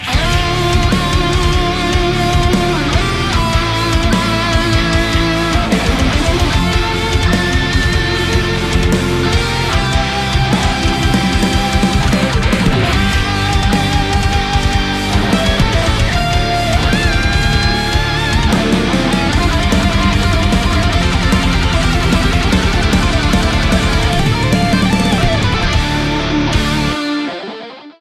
Metal cover